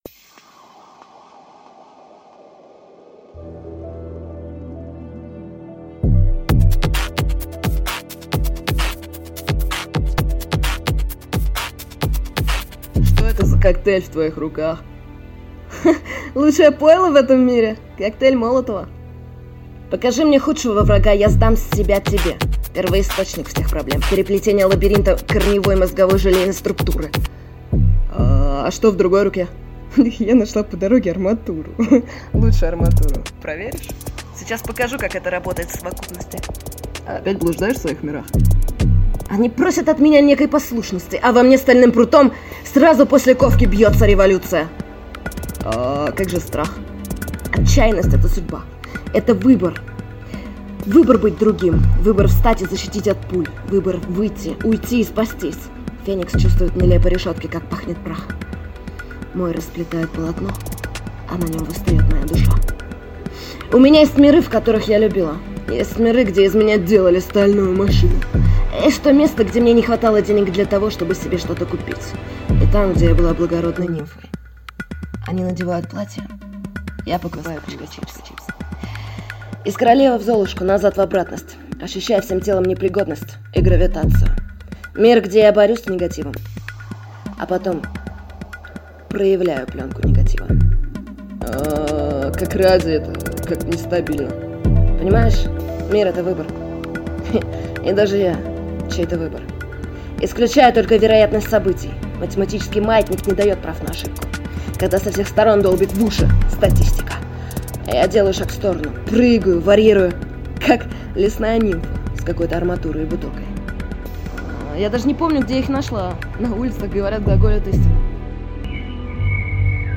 Аудиокнига Выбор | Библиотека аудиокниг